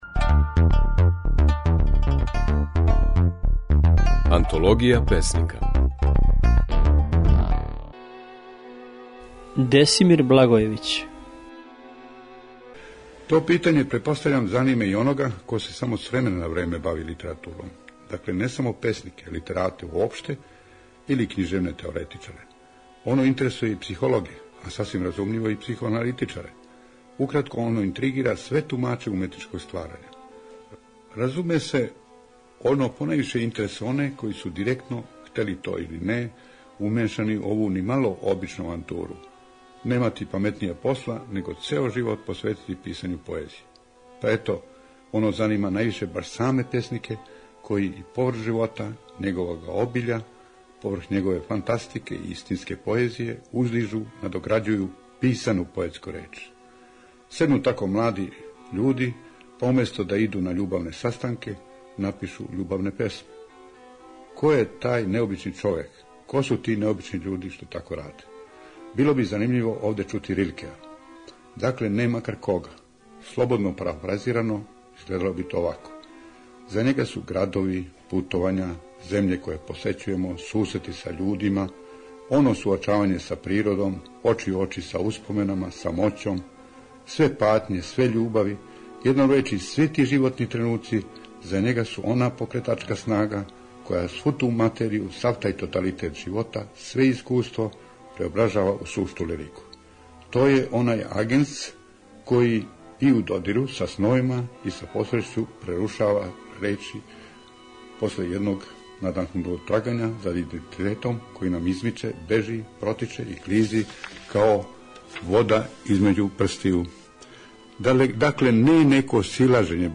Слушаћемо како је своје стихове говорио песник Десимир Благојевић (1905-1993).